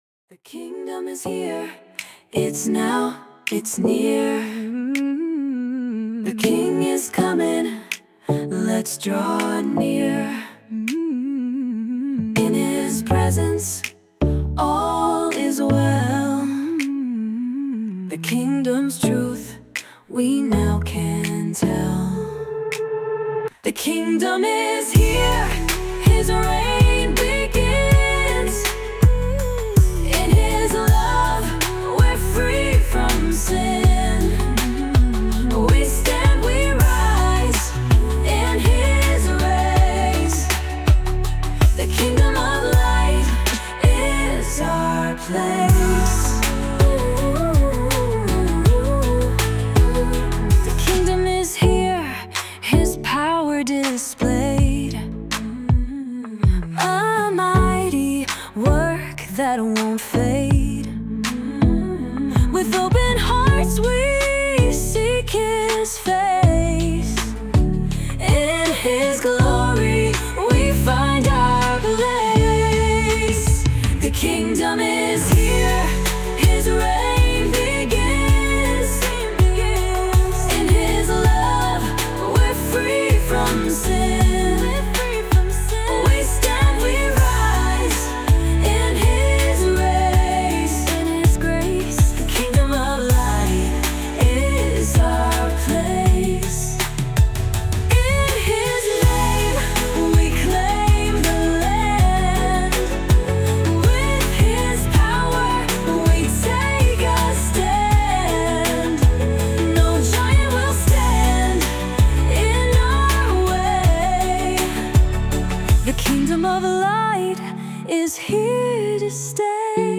With inspiring lyrics and a compelling melody